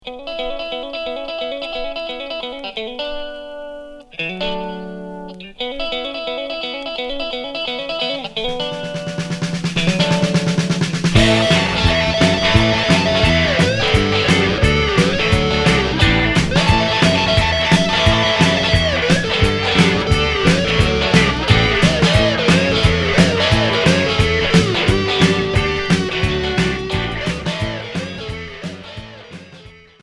Rock boogie Unique 45t retour à l'accueil